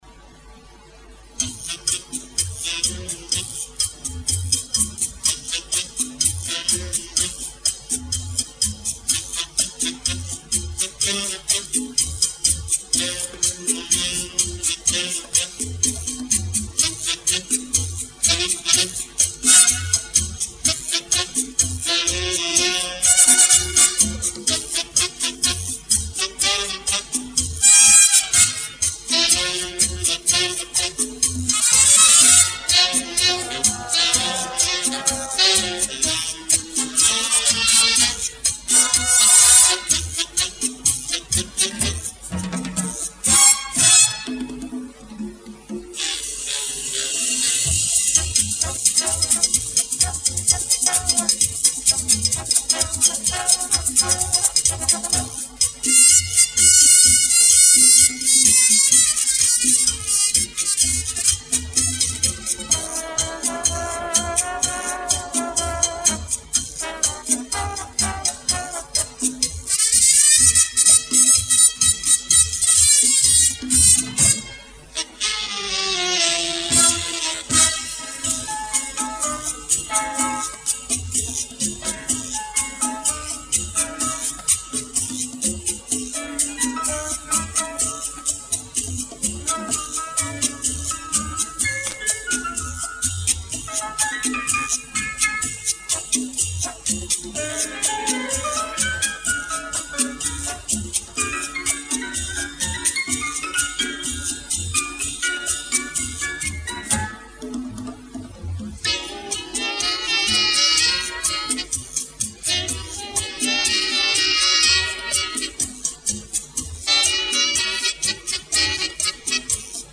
Некачественный оброзец прилагается